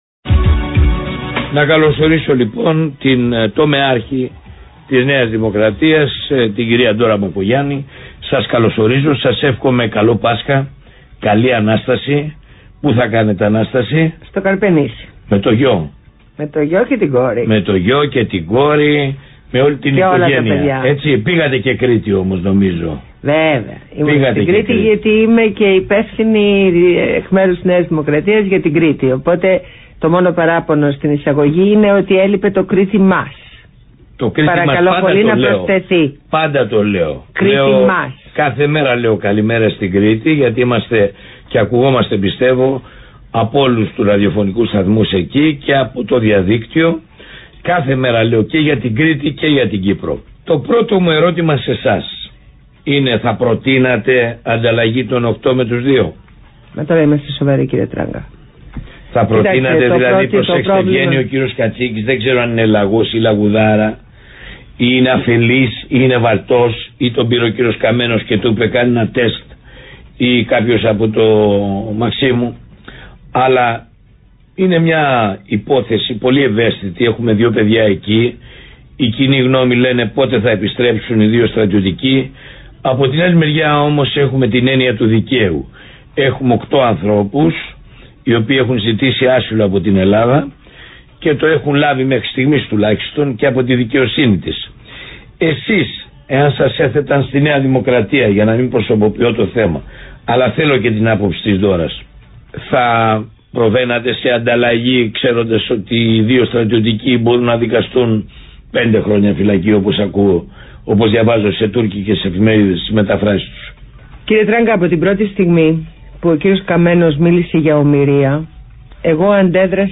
Συνέντευξη στο ραδιόφωνο Παραπολιτικά 90,1fm